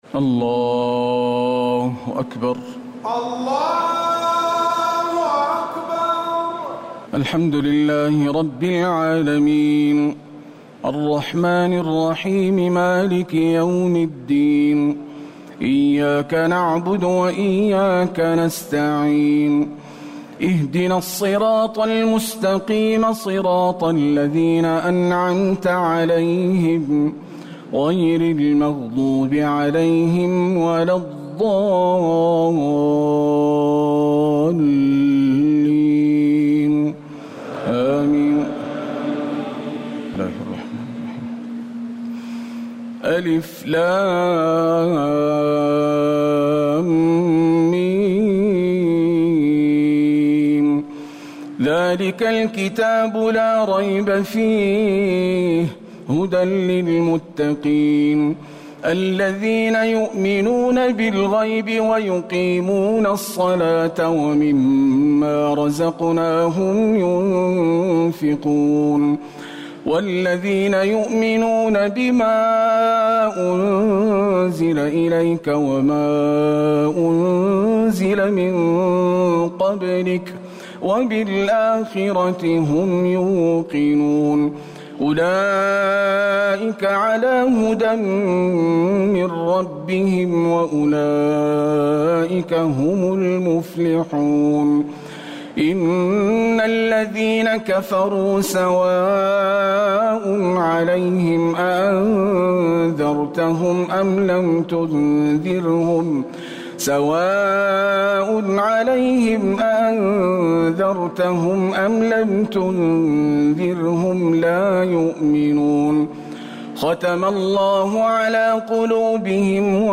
تراويح الليلة الأولى رمضان 1439هـ من سورة البقرة (1-86) Taraweeh 1st night Ramadan 1439H from Surah Al-Baqara > تراويح الحرم النبوي عام 1439 🕌 > التراويح - تلاوات الحرمين